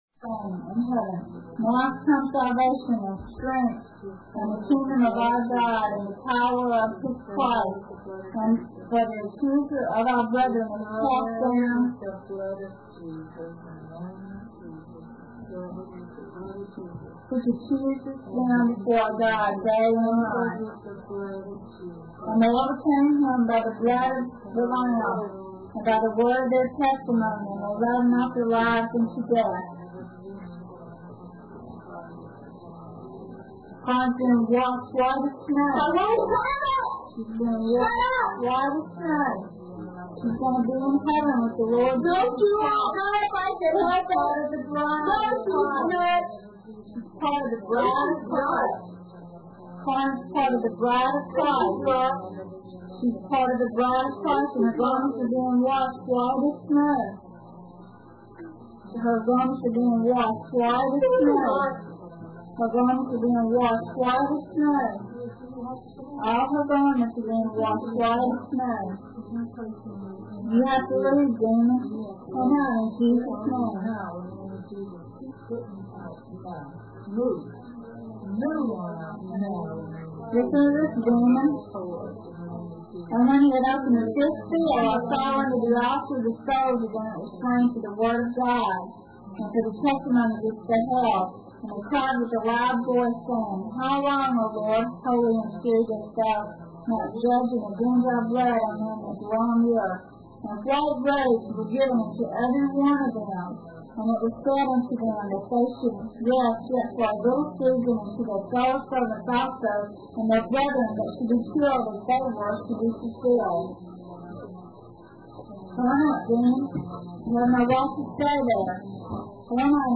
Here is a two hour blog talk radio interview done on March 1, 2012